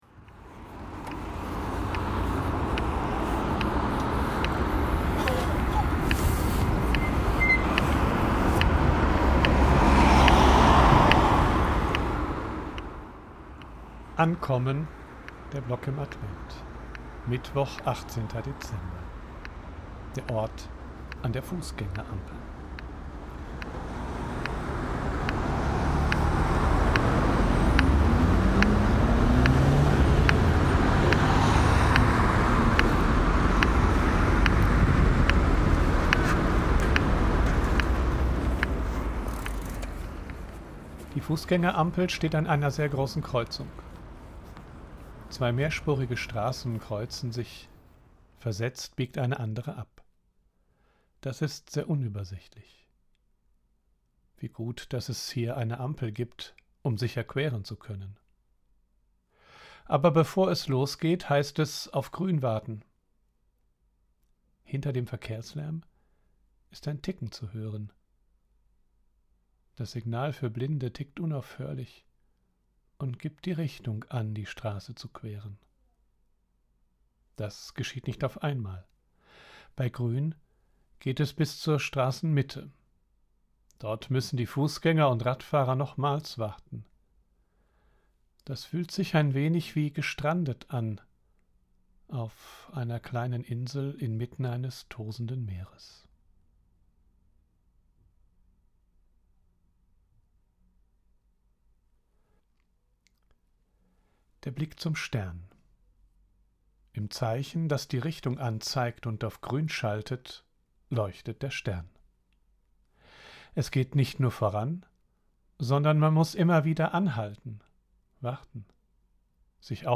Der Ort: An der Fußgängerampel
Hinter dem Verkehrslärm ist ein Ticken zu hören. Das Signal für Blinde tickt unaufhörlich und gibt die Richtung an, die Straße zu queren.